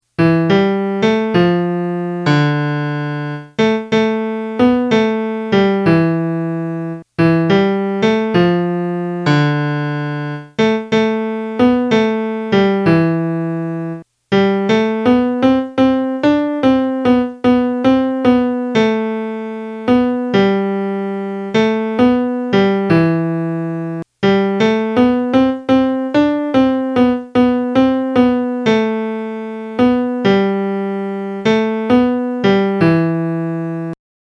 dove-sei-stato-melody.mp3